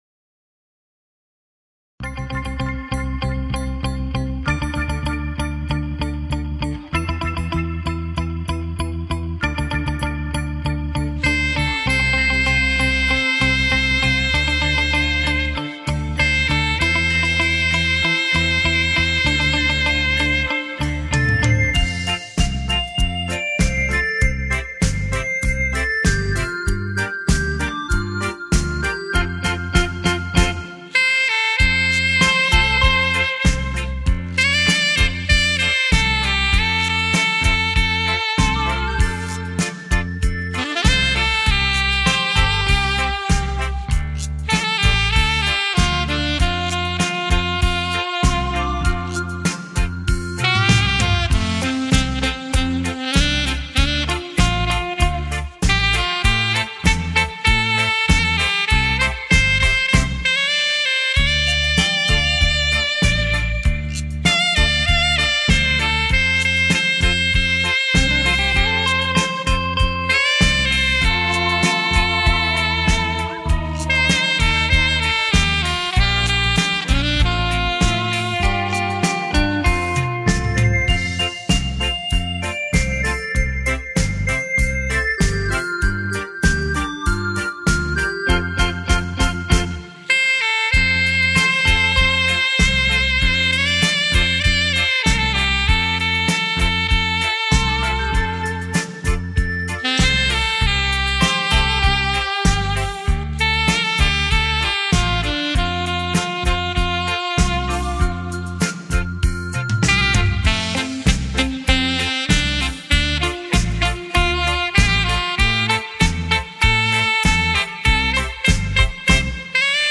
最受欢迎的台语音乐
随着萨克斯风乐音的吹奏，
可让聆听的人能很自然的以轻松无压的情绪进入到音乐之中。
这轻柔音乐如水般拂过你的全身，